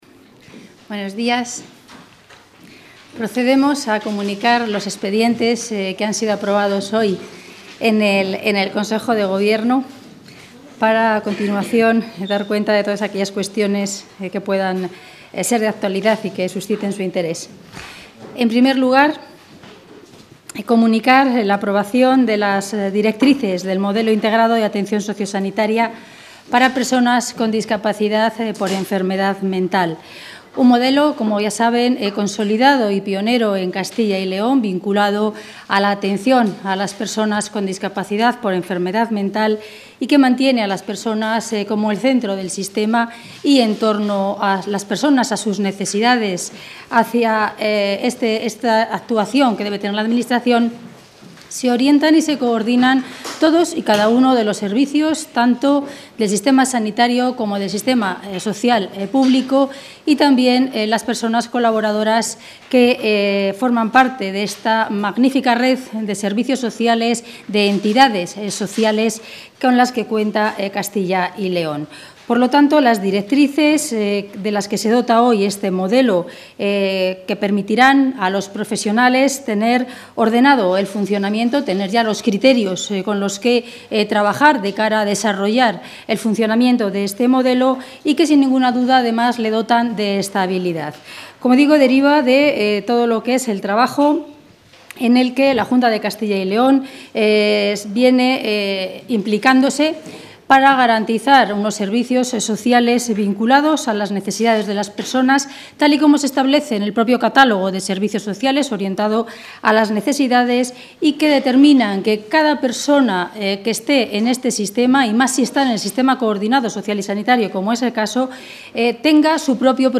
Audio rueda de prensa tras el Consejo de Gobierno.